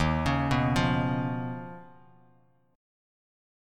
Eb7sus2#5 chord